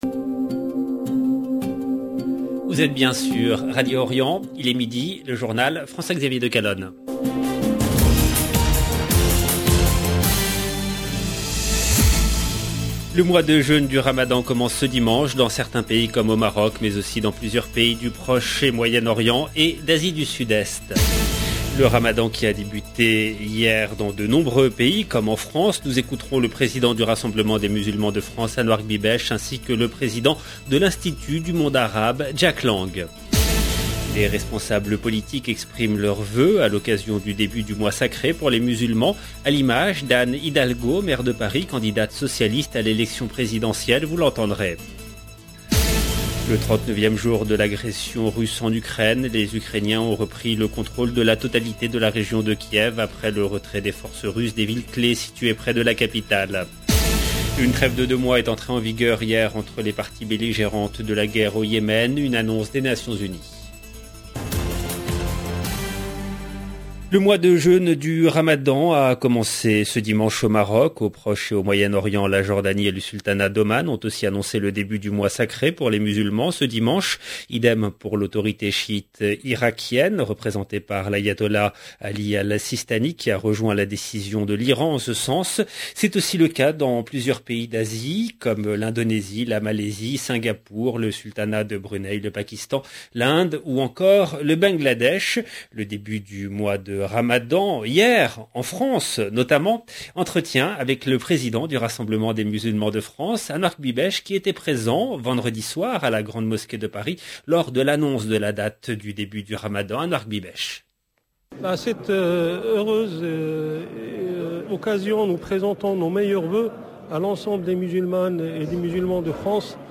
LE JOURNAL EN LANGUE FRANCAISE DE MIDI DU 3/04/22